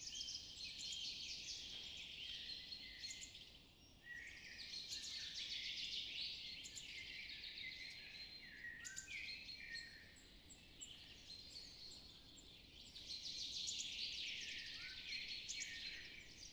rainforest-birds-2.wav